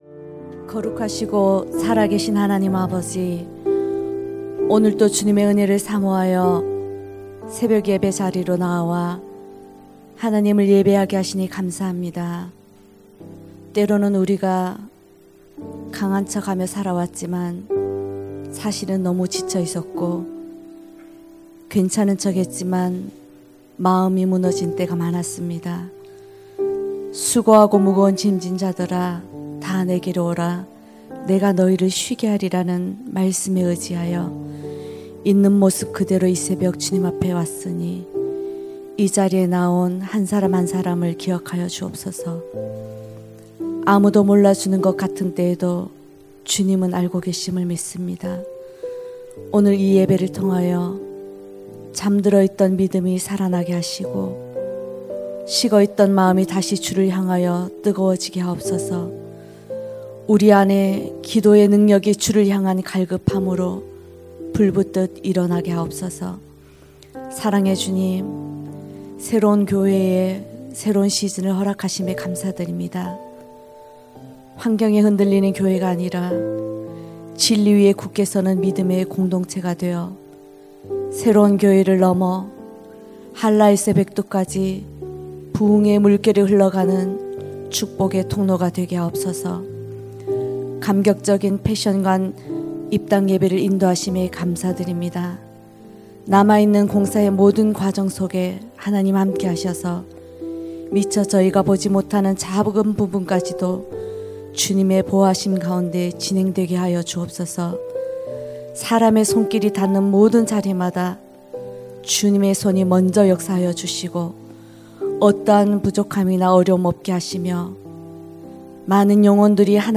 > 설교 - 새로운교회